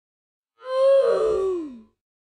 Roblox Death Sound (slow)